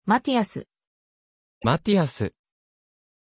Pronunciación de Mattias
pronunciation-mattias-ja.mp3